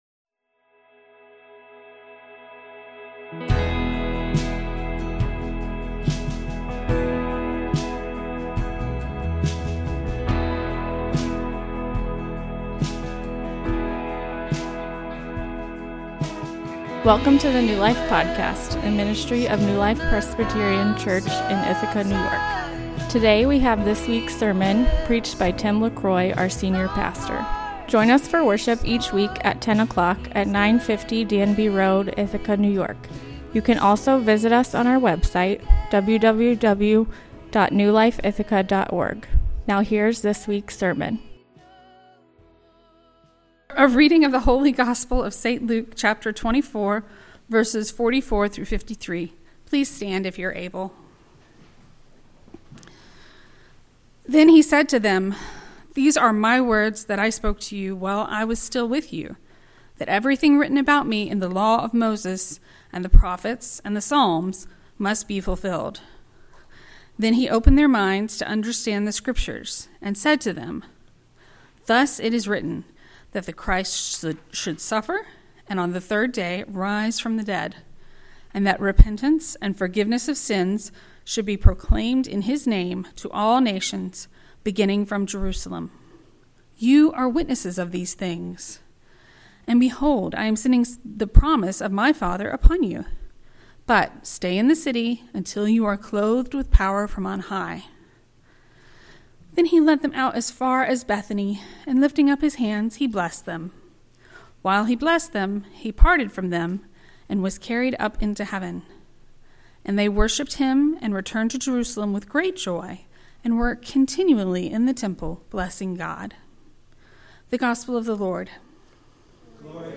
A sermon on the Ascension of Jesus